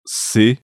Maneira de articulação: fricativa (os articuladores se aproximam para que se produza uma fricção na passagem do ar).
Lugar de articulação: dental (ápice da língua e dentes superiores) ou alveolar (ápice da língua e alvéolos).
Estado da glote: desvozeado (não há vibração das pregas vocais).